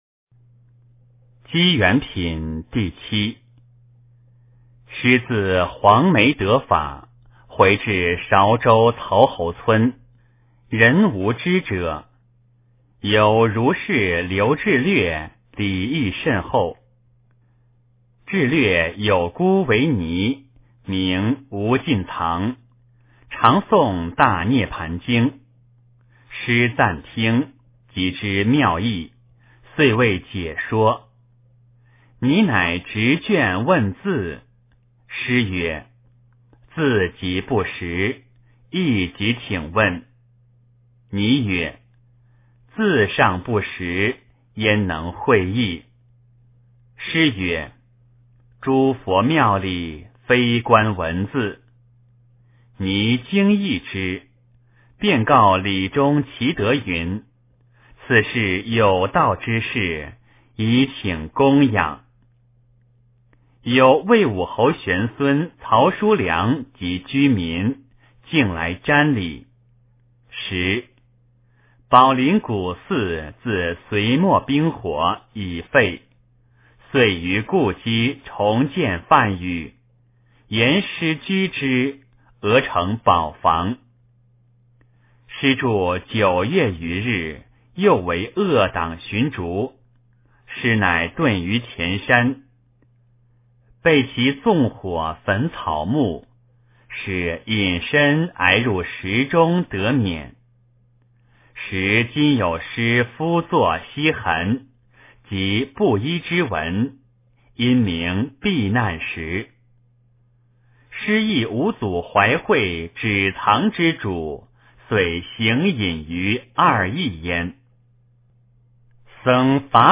坛经（机缘品） - 诵经 - 云佛论坛